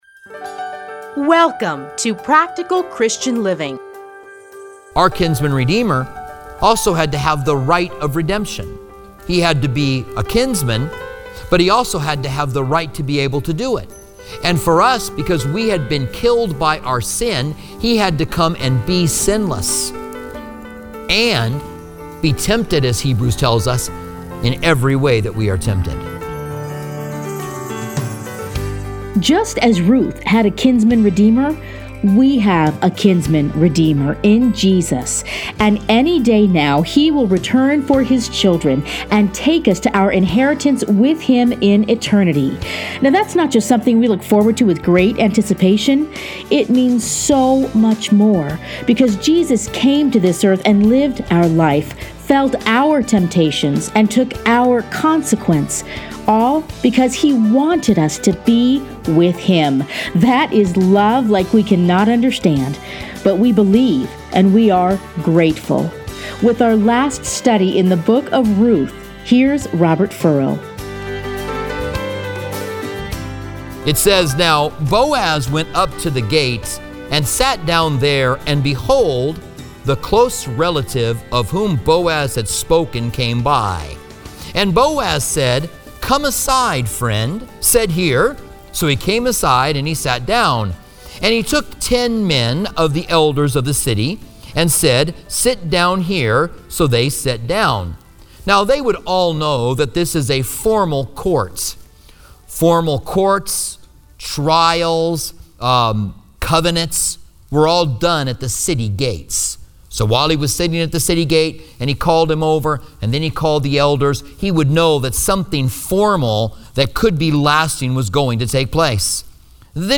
Listen here to a teaching from Ruth.